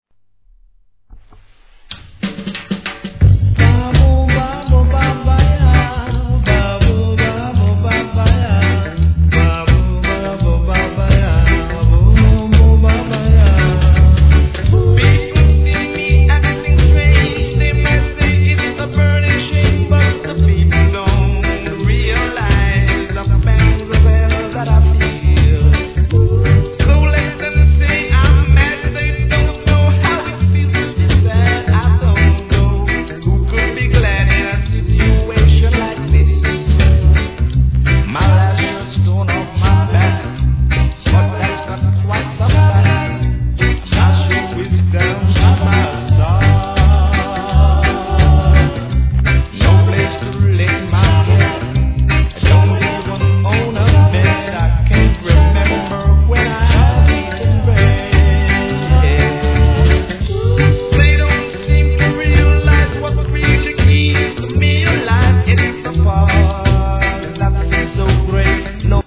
REGGAE
イントロのスキャット、そして素晴らしいコーラス・ハーモニーにやられます!